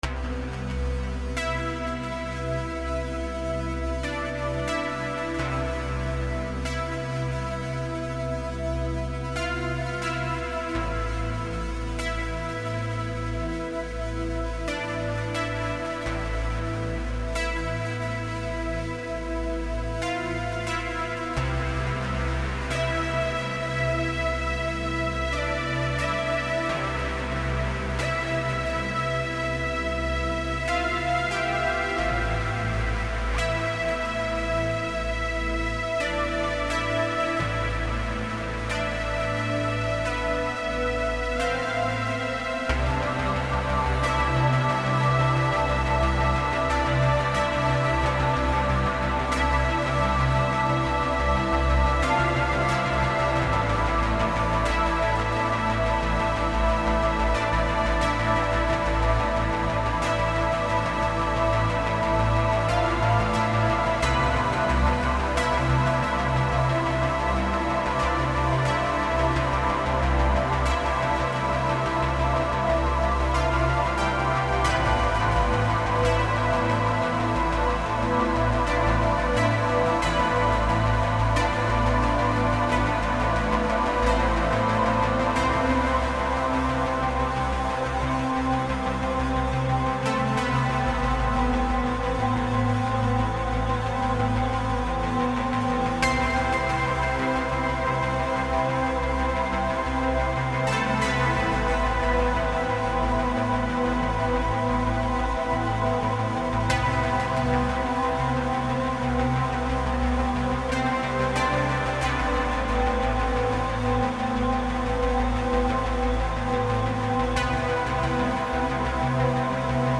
Genre: Downtempo, New Age, Ambient.